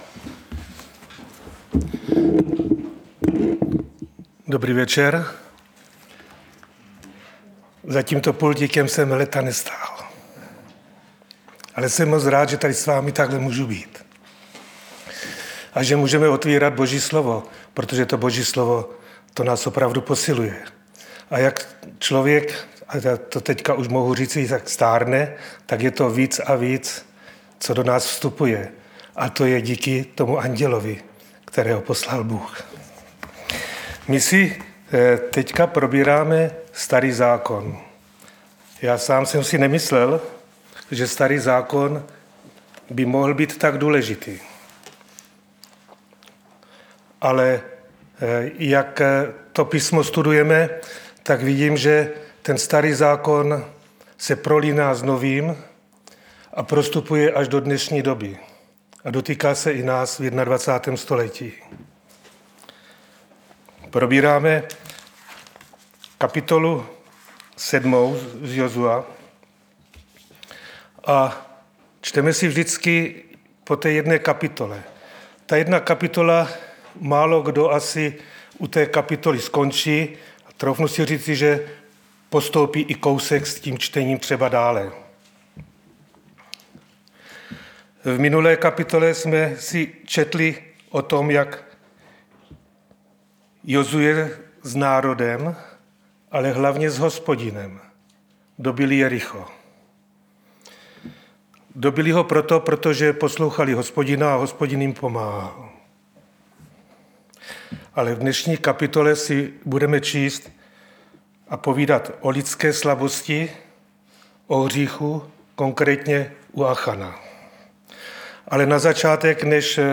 Středeční vyučování